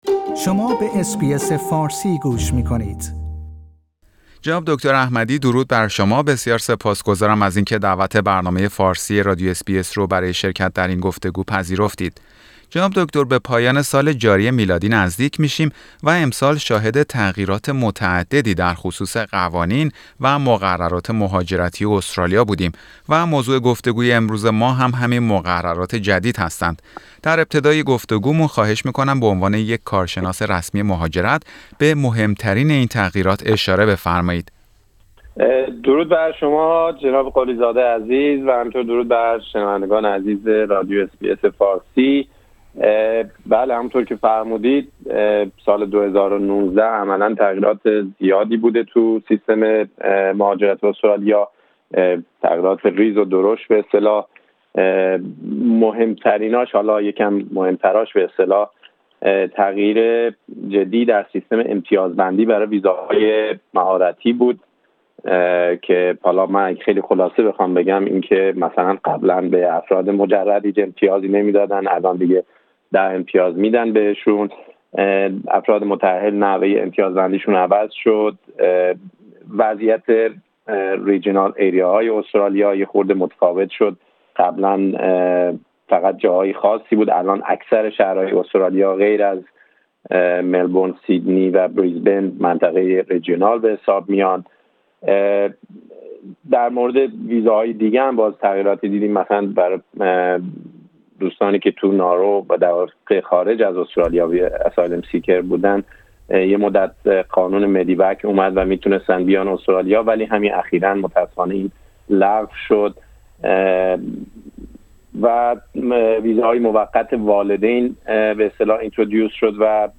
برنامه فارسی رادیو اس بی اس در همین خصوص گفتگویی داشت